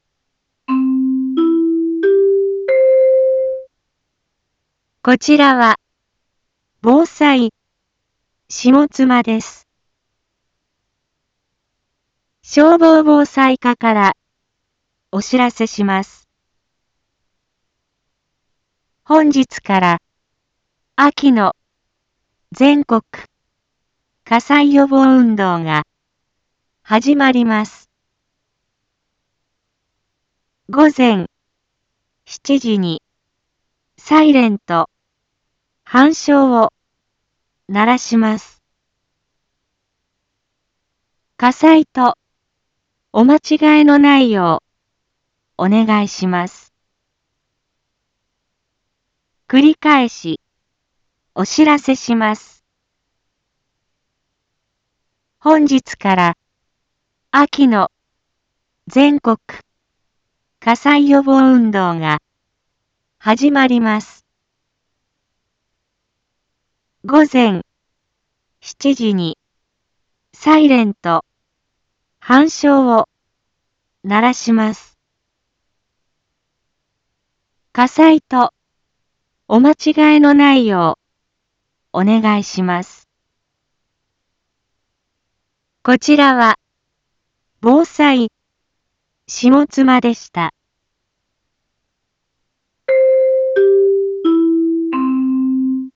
一般放送情報
Back Home 一般放送情報 音声放送 再生 一般放送情報 登録日時：2025-11-09 06:46:57 タイトル：秋季全国火災予防運動に伴うサイレン吹鳴 インフォメーション：こちらは、ぼうさいしもつまです。